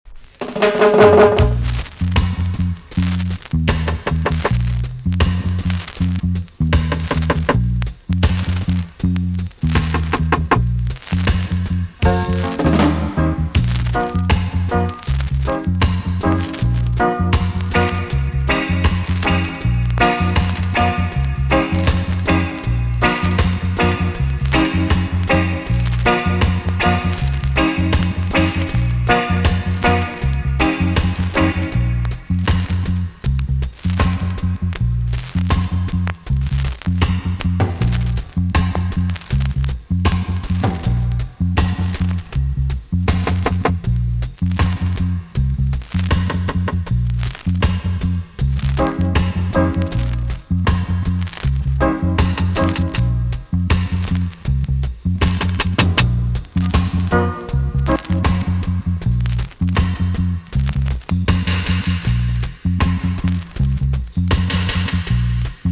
TOP >80'S 90'S DANCEHALL
B.SIDE Version
VG+ 少し軽いヒスノイズが入ります。